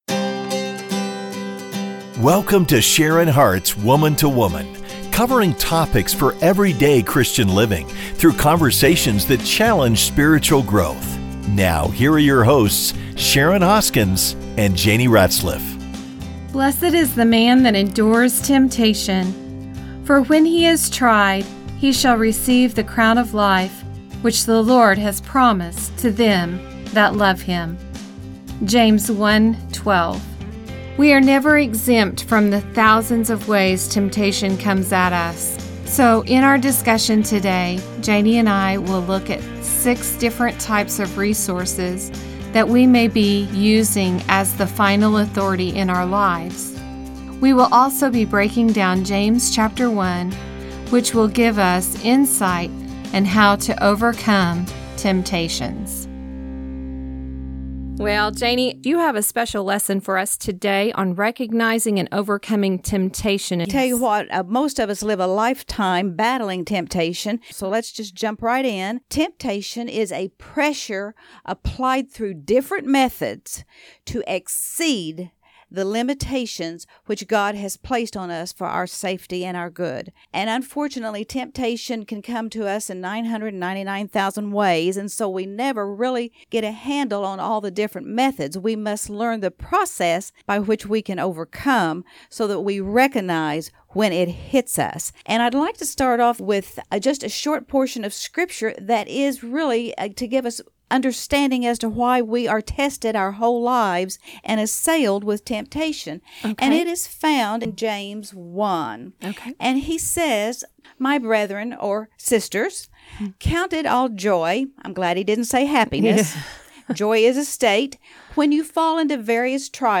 So, join in the conversation as we dig into the scriptures that will give us insight into how to overcome temptations.